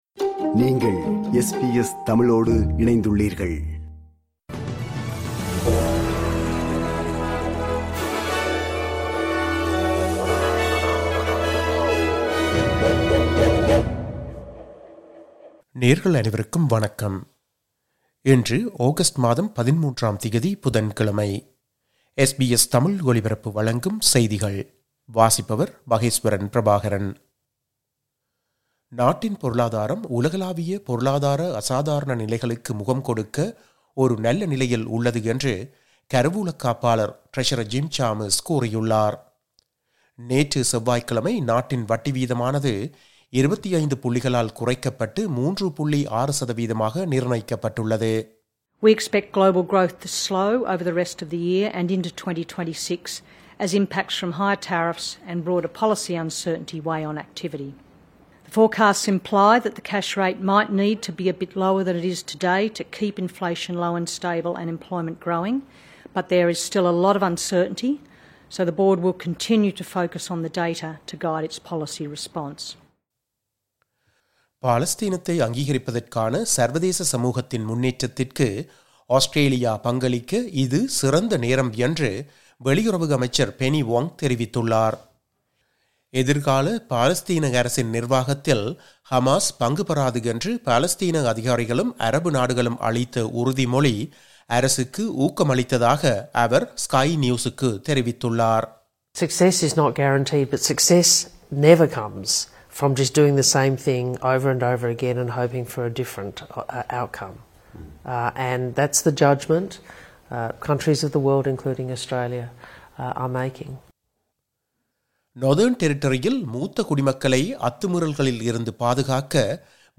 SBS தமிழ் ஒலிபரப்பின் இன்றைய (புதன்கிழமை 13/08/2025) செய்திகள்.